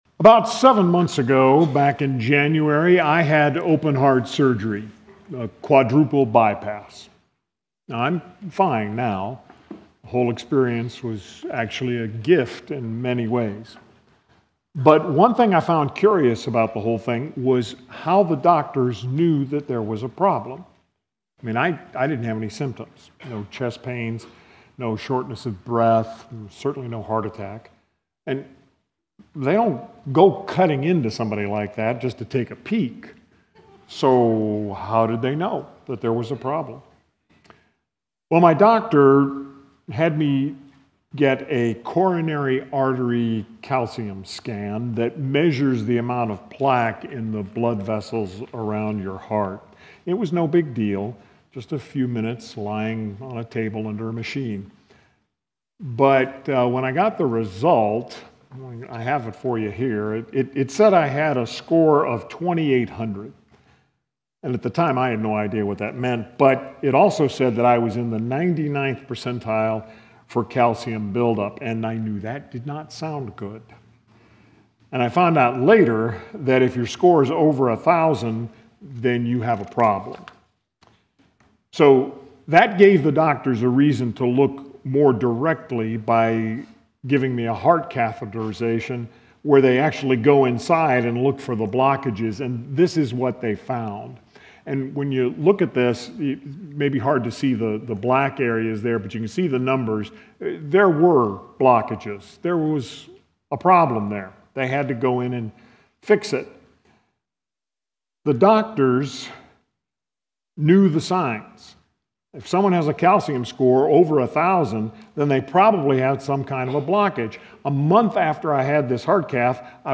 Prev Previous Sermon Next Sermon Next Title Know the Signs